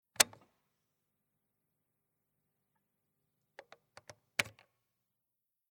"Logos 58" electric desktop calculator
0149_Ein-_und_Ausschaltknopf.mp3